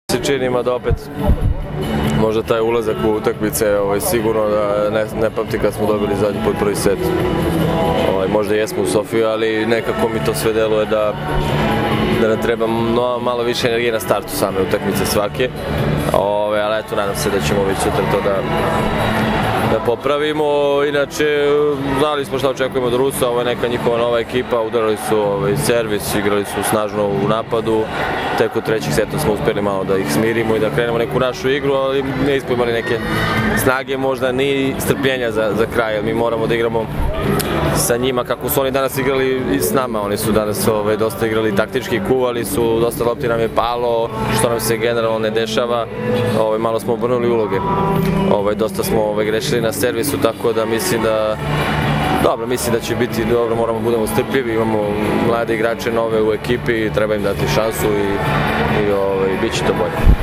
IZJAVA BOJANA JANIĆA